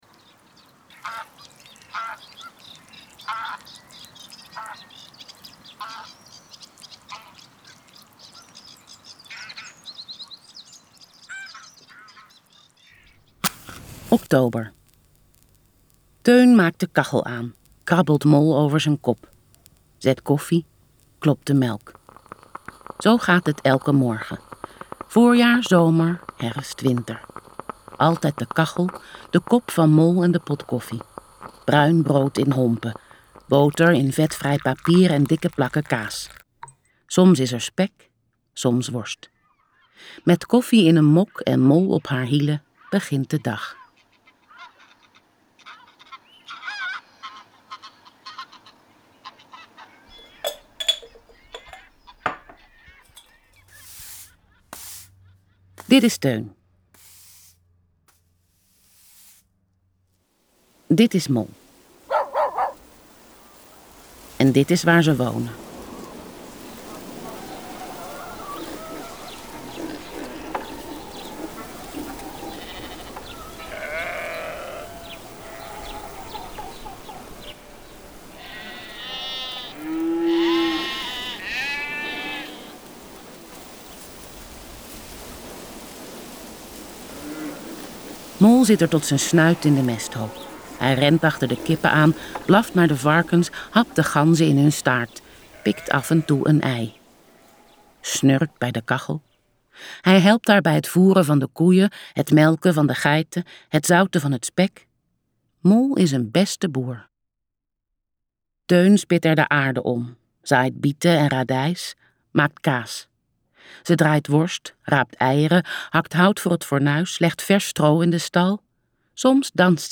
luisterboek
Luisterboek